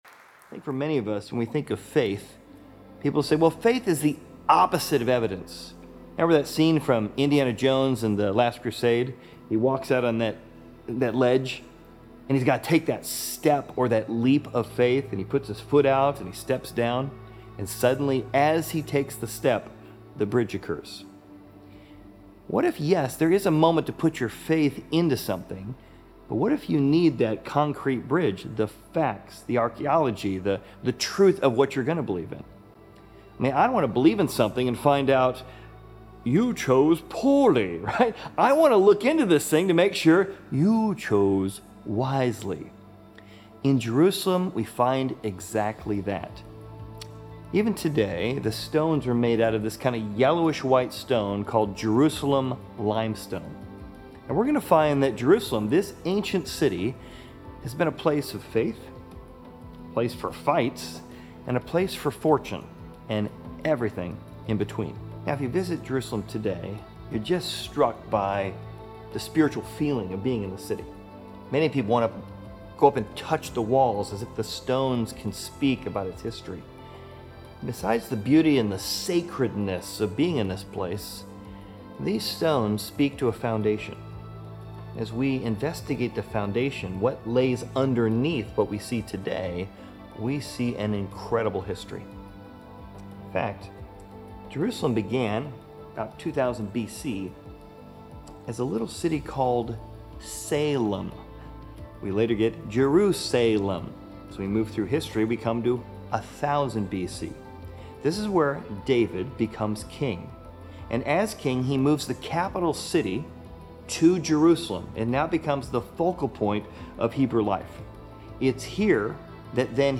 Exploring Service / Raiders of The Lost Cities / Jerusalem